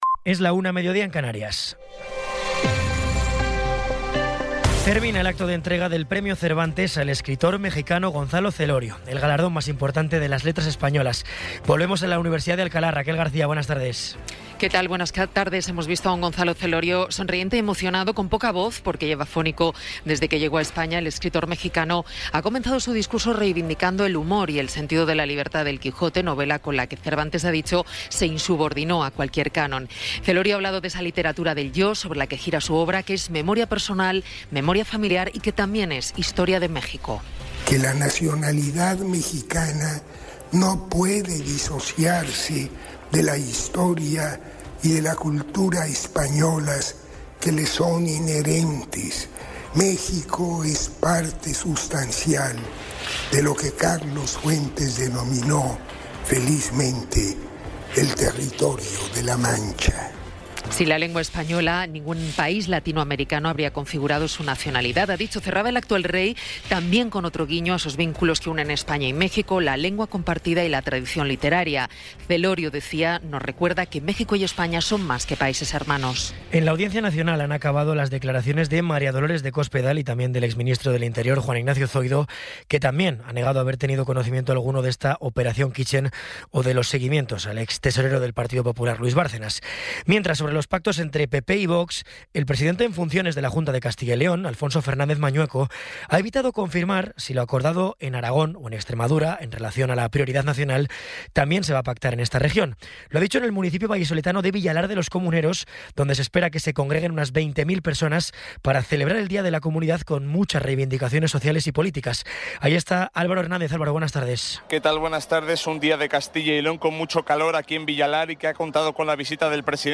Resumen informativo con las noticias más destacadas del 23 de abril de 2026 a la una de la tarde.